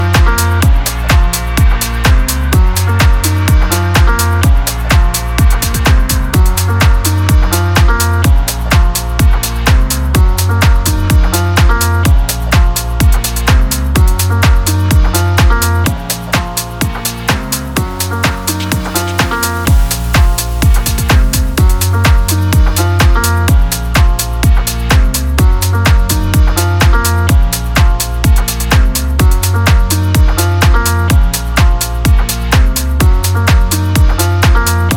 Жанр: Хаус
# House